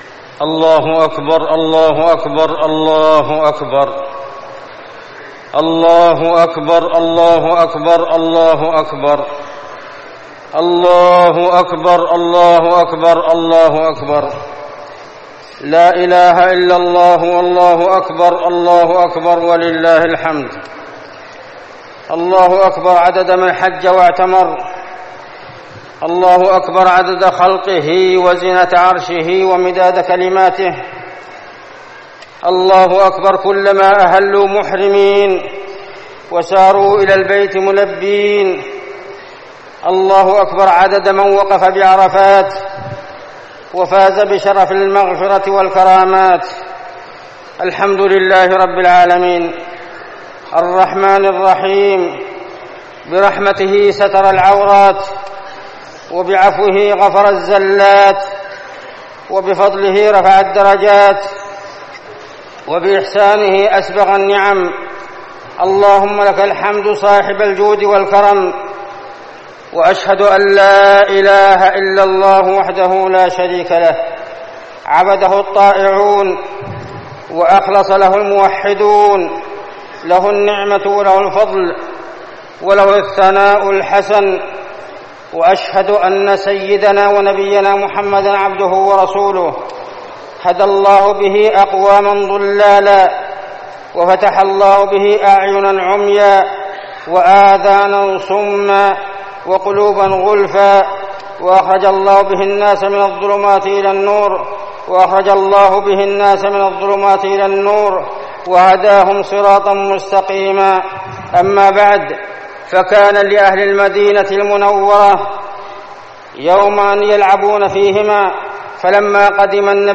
خطبة عيد الأضحى - المدينة - الشيخ عبدالله الزاحم
تاريخ النشر ١٠ ذو الحجة ١٤١٥ هـ المكان: المسجد النبوي الشيخ: عبدالله بن محمد الزاحم عبدالله بن محمد الزاحم خطبة عيد الأضحى - المدينة - الشيخ عبدالله الزاحم